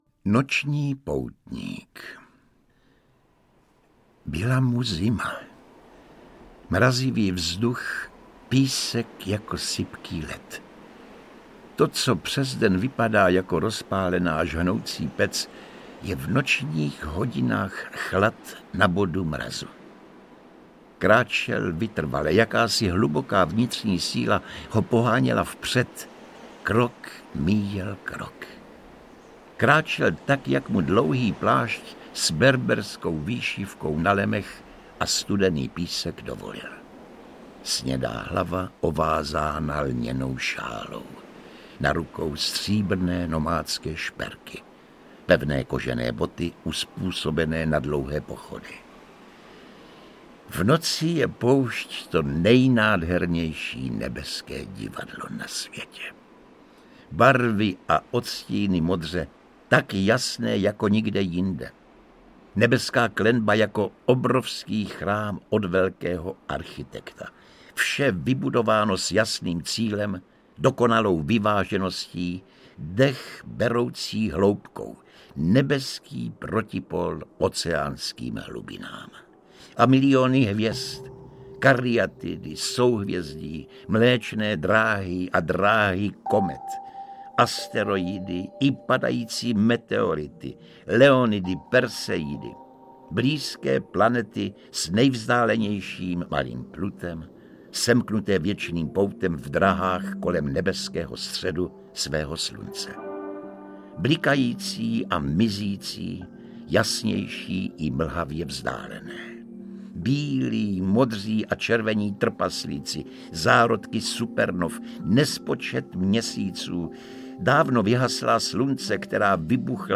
Pod hvězdami audiokniha
Ukázka z knihy
• InterpretJosef Somr, Viktor Preiss